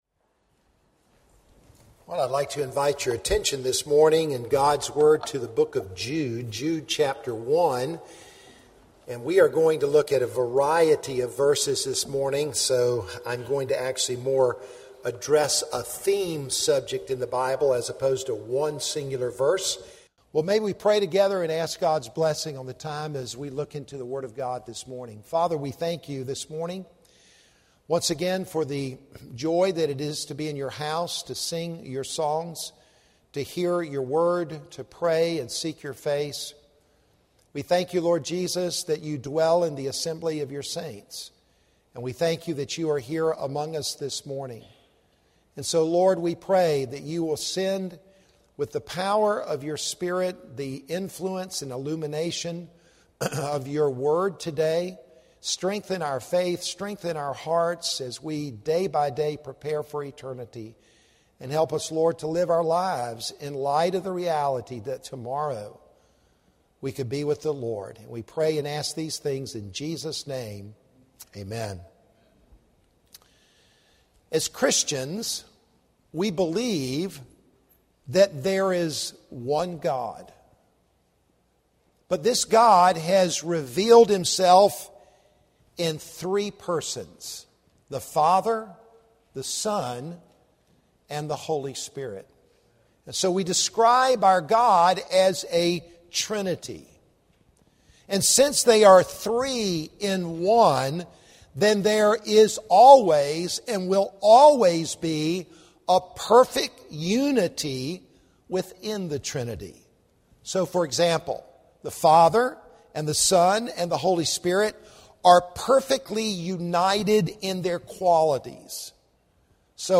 Download Audio Sanctification by the Father, Son, & Holy Spirit Mar 21 Romans Romans 8:9-13 Resource Info Topic Sunday AM Scripture Romans Date March 21, 2021 Stay up to date with “ Limerick Chapel Sunday Sermons ”: